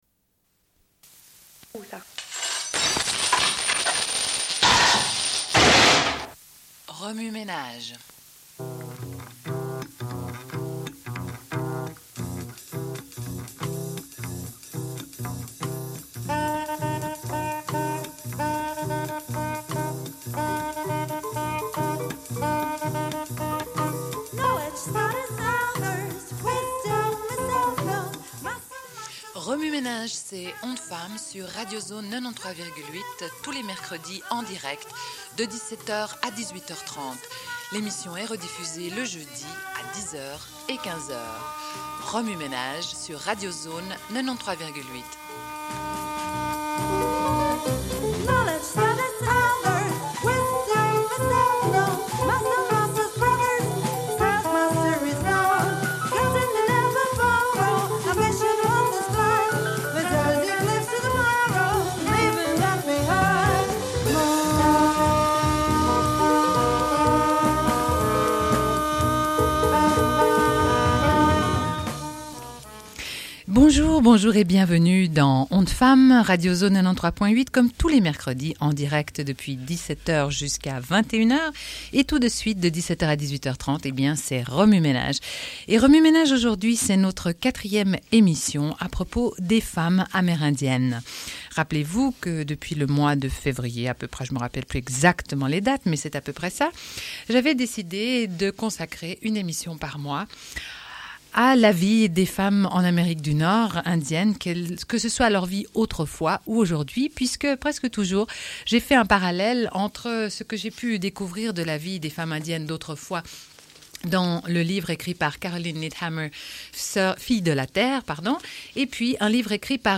Une cassette audio, face A00:31:27